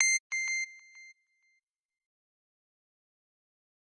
pda_news.ogg